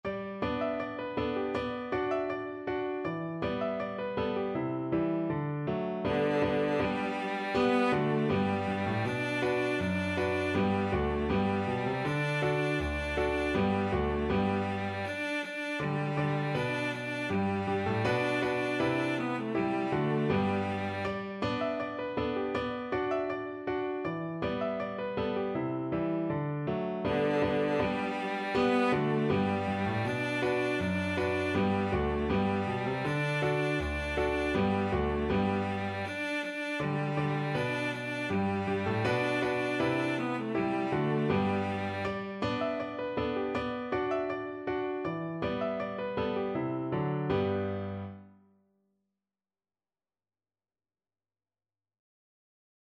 Cello version
Steadily =c.80
2/4 (View more 2/4 Music)
Traditional (View more Traditional Cello Music)